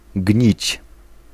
Ääntäminen
US GenAM: IPA : [ɹɑt]